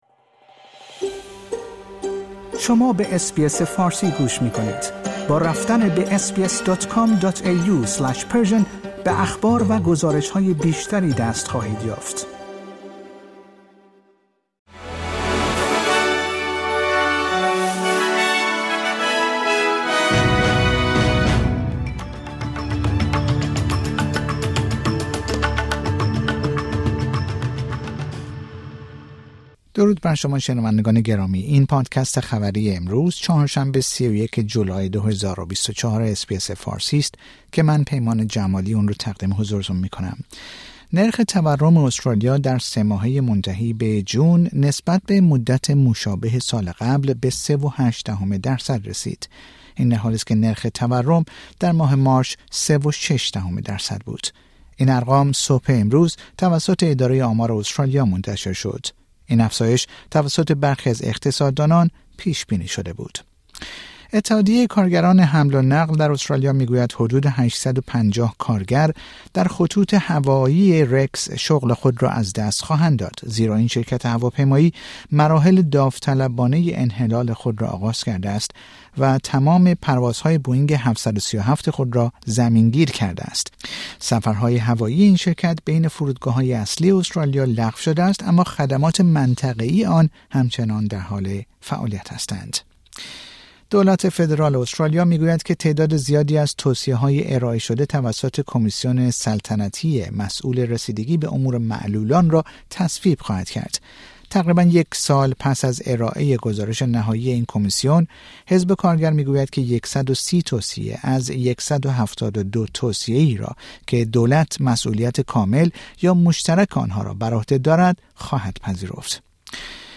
در این پادکست خبری مهمترین اخبار استرالیا در روزچهارشنبه ۳۱ جولای ۲۰۲۴ ارائه شده است.